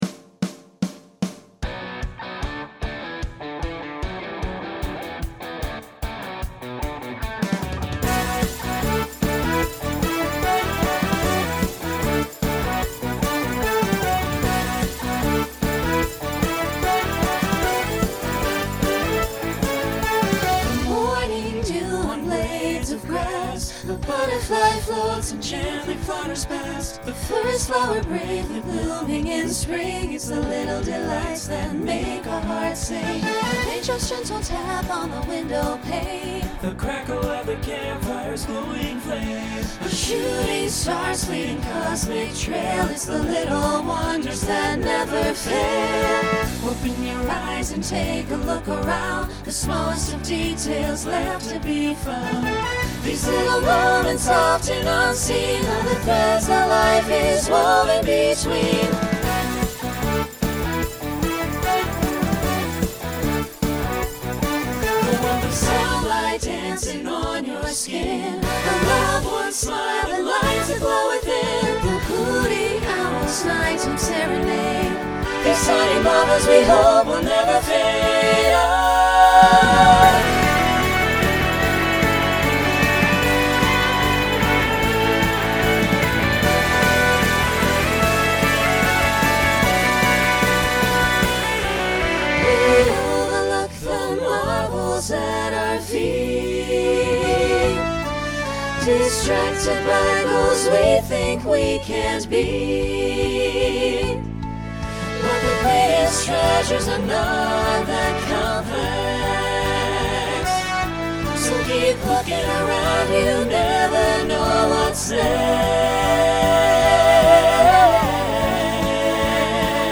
Genre Rock Instrumental combo
Original Song Show Function Opener Voicing SATB